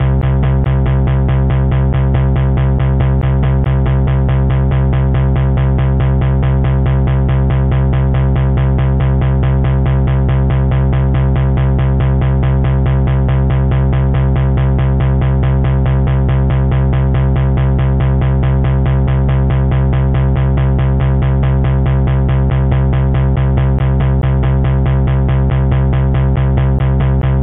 快速重低音140
描述：一条很好的低音线，很适合在轨道上出现一个很好的重型快速的低音。用一个合成器低音效果器制作。与Open Hat节奏140配合使用，效果极佳；D
Tag: 140 bpm Techno Loops Bass Synth Loops 4.62 MB wav Key : Unknown